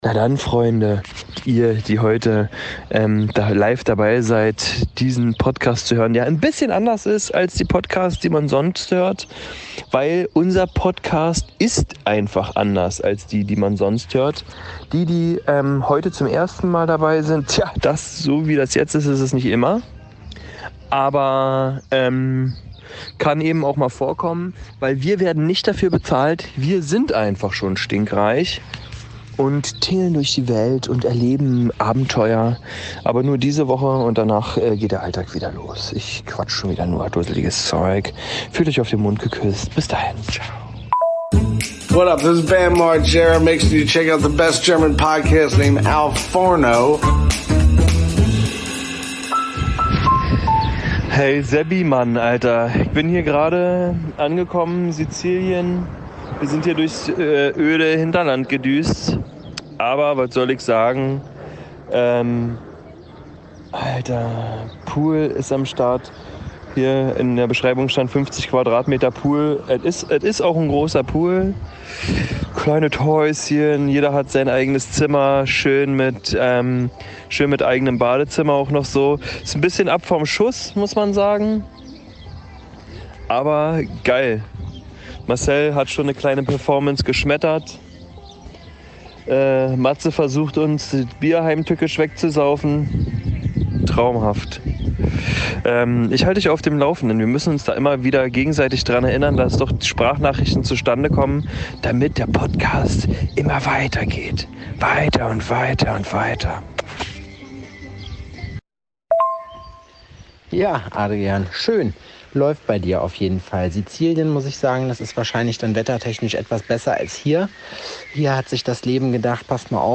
Beide Atzen im Urlaub und Unterhaltung findet via WhatsApp-Tagebuch statt - ähnlich wie in Haus am See, allerdings weniger schnulzig.